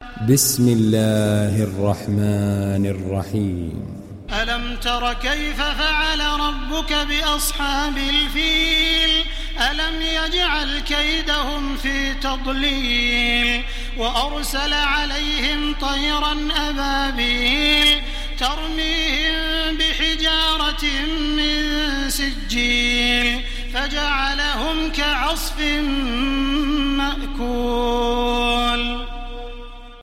Fil Suresi İndir mp3 Taraweeh Makkah 1430 Riwayat Hafs an Asim, Kurani indirin ve mp3 tam doğrudan bağlantılar dinle
İndir Fil Suresi Taraweeh Makkah 1430